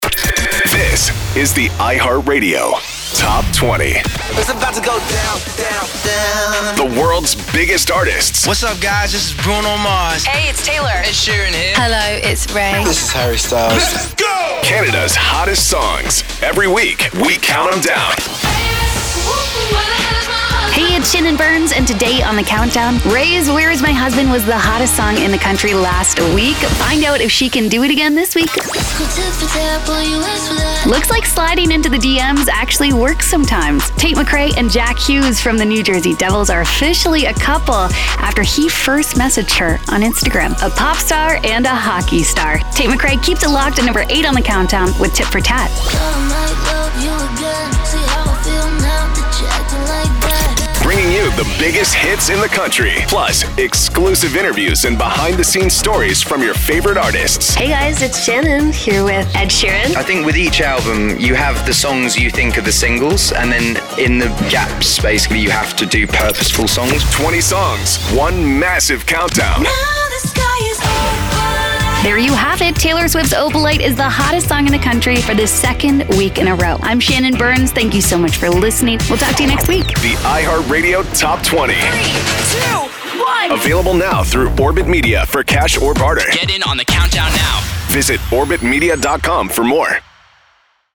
iHeartRadio-Top-20-Orbyt-Media-Sizzle-Reel.mp3